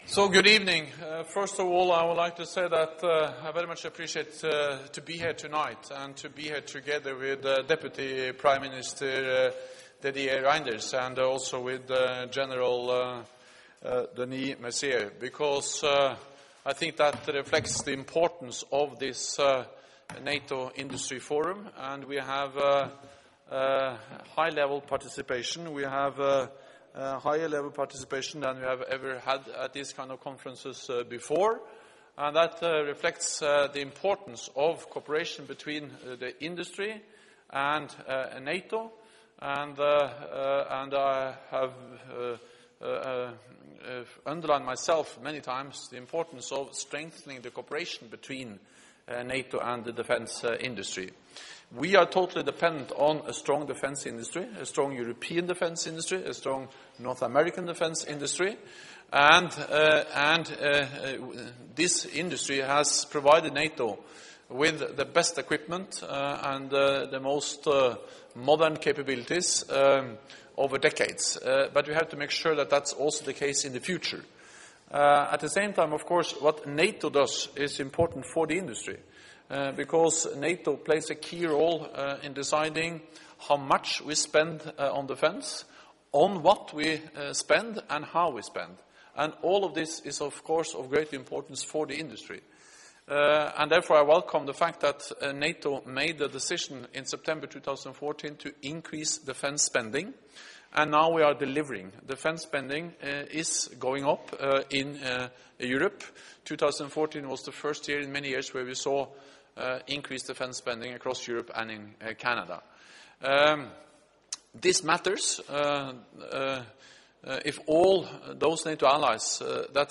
Joint press conference with NATO Secretary General Jens Stoltenberg, Supreme Allied Commander Transformation, General Denis Mercier and Belgian Foreign Minister, Didier Reynders at the NATO-Industry Forum - Secretary General's opening remarks
(As delivered)